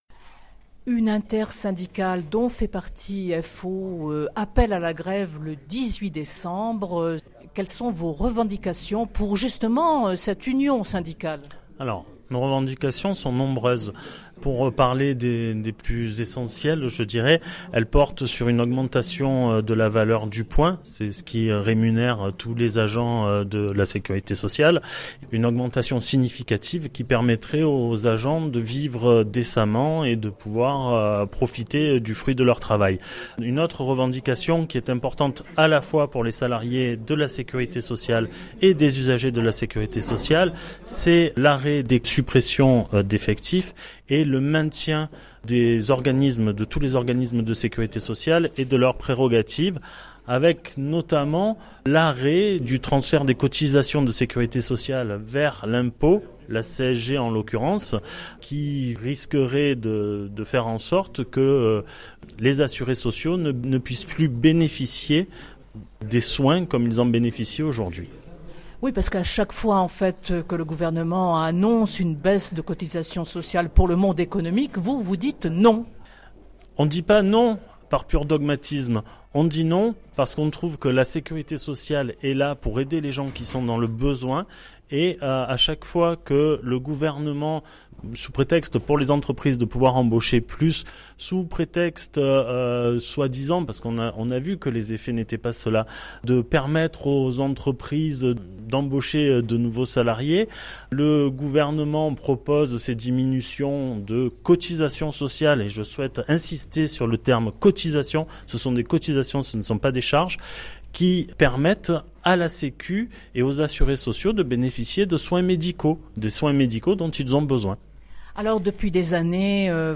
Entretien…